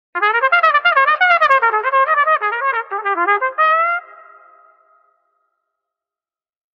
Notification Sounds / Sound Effects
Silly-trumpet-sound-effect.mp3